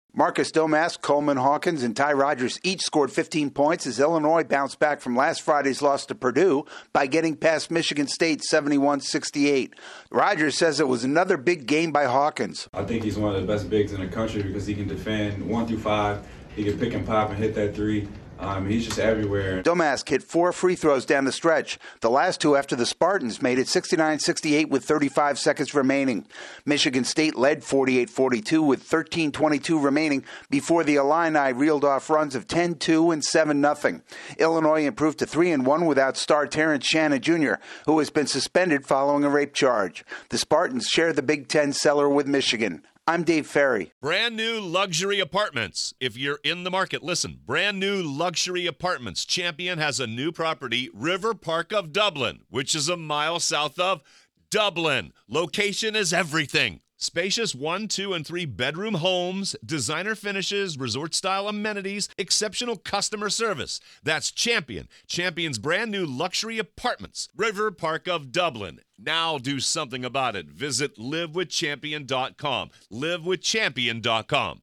Illinois makes a late push to beat Michigan State. AP correspondent